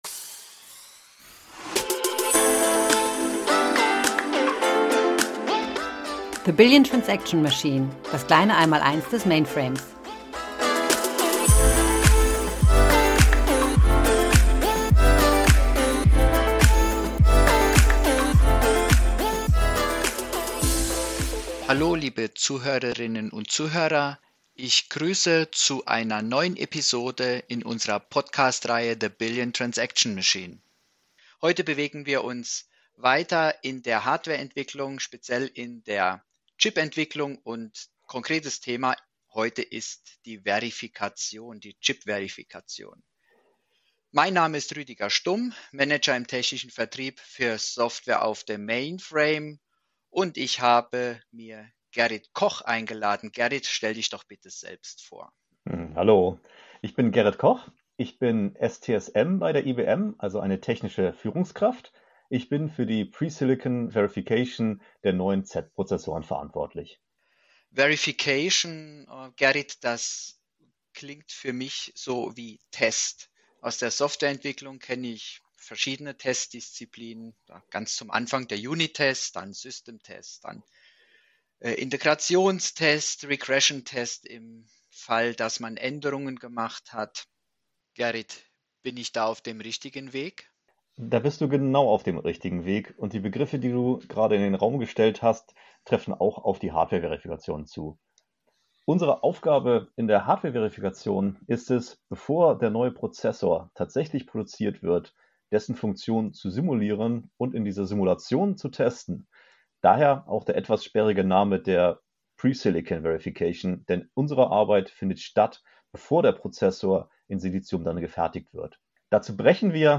Darüber sprechen wir in dieser Episode mit einem
Experten aus der Chipentwicklung im deutschen IBM Labor in